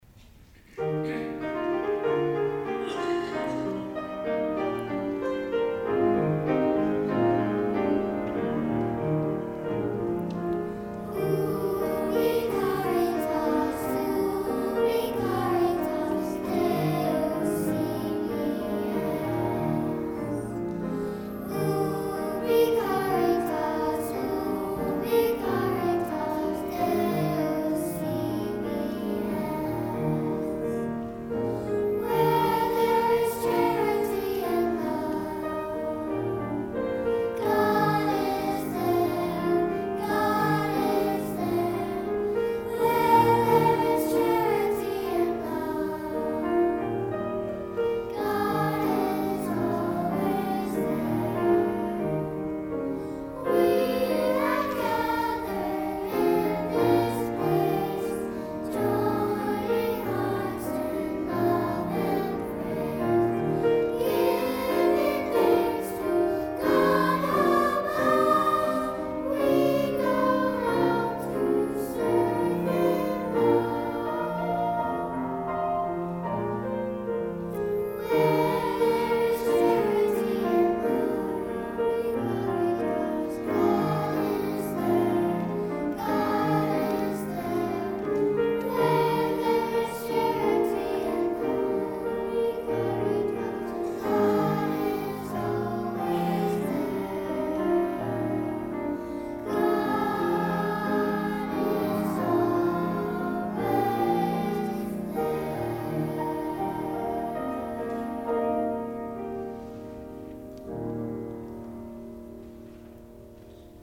Junior Choir
piano